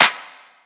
DDW2 CLAP 1.wav